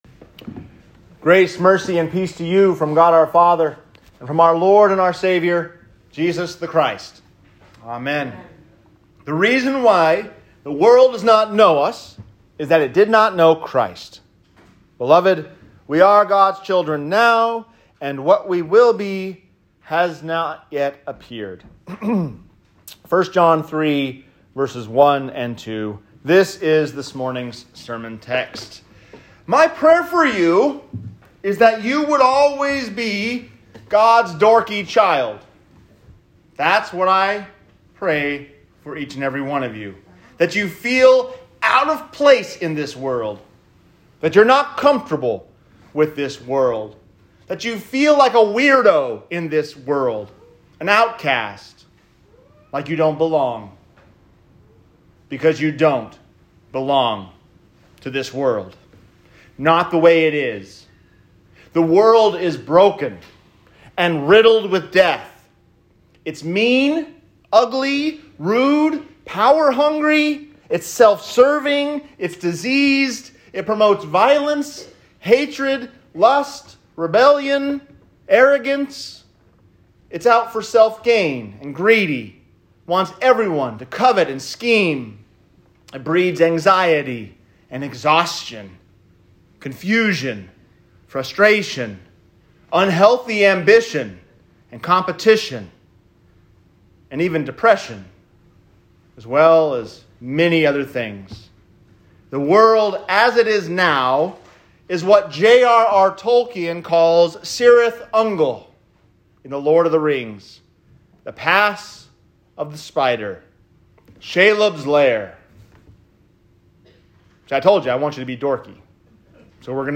11-7-21-sermon_all-saints-day-obs..m4a